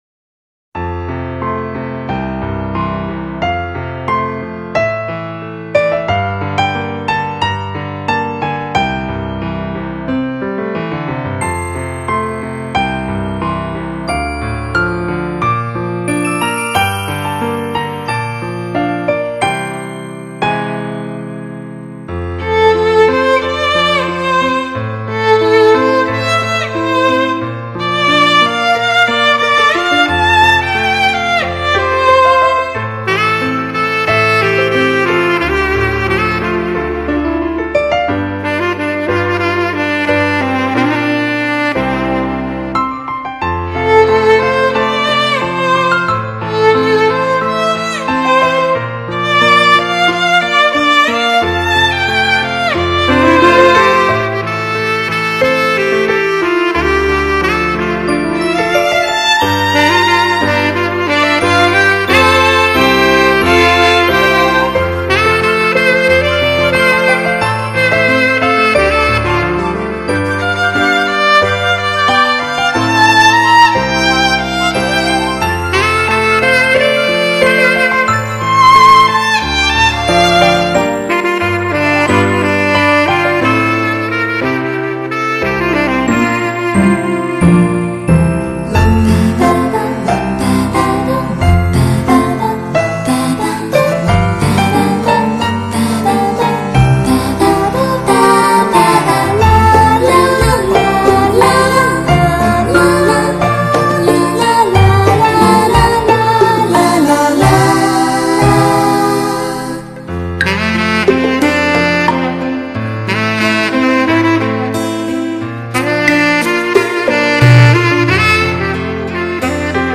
演奏流行歌曲纯音乐轻音乐HiFi发烧碟CD专辑
金属质感的萨克斯慑人心魂，柔美动听小提琴激荡胸怀，完美融合，精彩绝美。
这种形式的二重奏很罕见，两种乐器结合相辅相融，层次鲜明，音色刚柔并济。
旋律起伏绵延，基调静中有动。